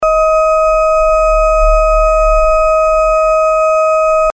• ▲ ▼ Das ist der Ton, der das Signal überlagert.